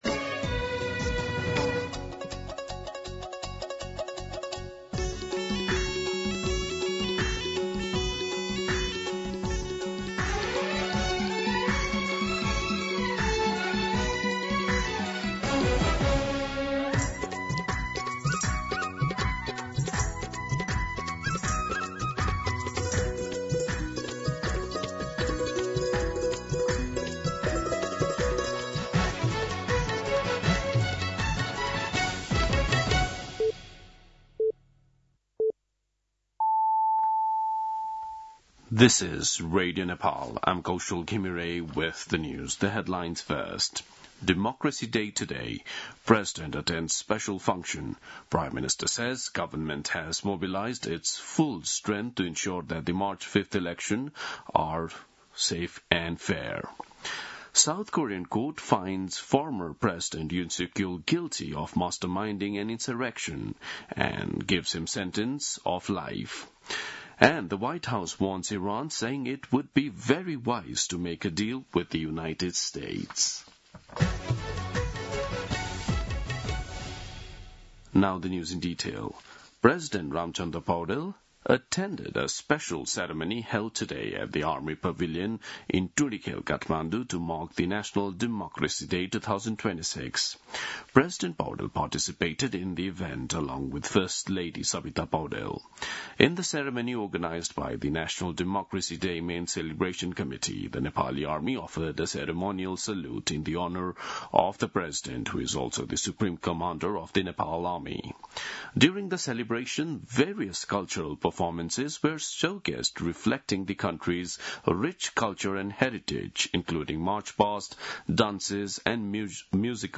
दिउँसो २ बजेको अङ्ग्रेजी समाचार : ७ फागुन , २०८२
2pm-News-07.mp3